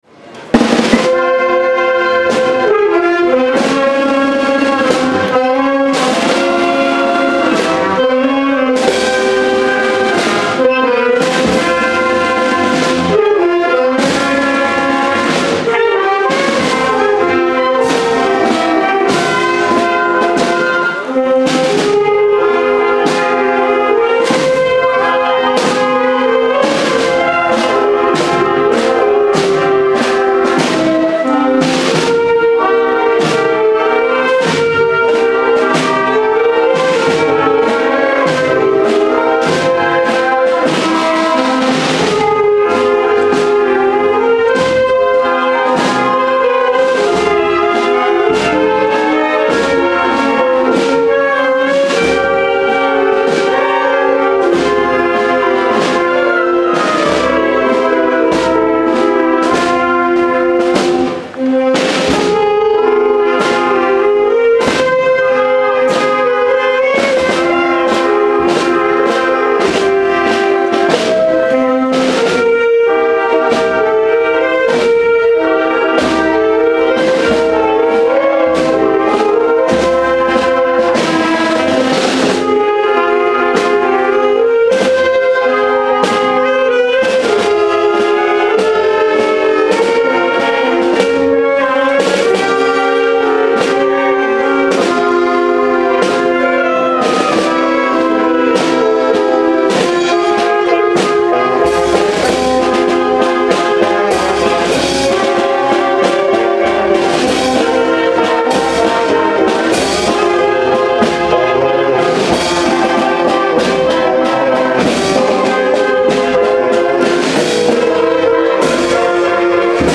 Autoridades municipales, civiles y religiosas de la localidad, y cientos de totaneros, han acompañado en procesión a la imagen de Santa Eulalia de Mérida hasta su llegada a la Parroquia de Santiago, donde ha sido recibida con todos los honores tras su traslado en procesión desde la Ermita de San Roque.
Desde primera hora de la tarde una comitiva institucional presidida por la alcaldesa de Totana, Isabel María Sánchez, ediles de la Corporación Municipal, autoridades religiosas, miembros de la Fundación La Santa y la Agrupación Municipal de Música de Totana, han llegado a la ermita de San Roque, donde tras el encuentro con la imagen de Santa Eulalia la han acompañado en procesión hasta el templo parroquial.